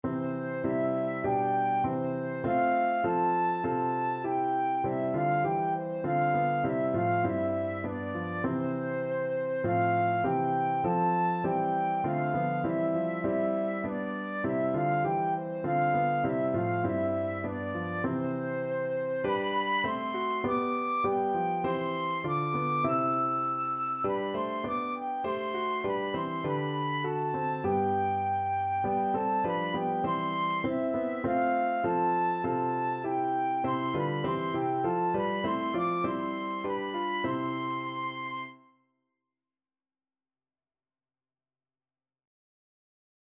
Flute
4/4 (View more 4/4 Music)
C major (Sounding Pitch) (View more C major Music for Flute )
Traditional (View more Traditional Flute Music)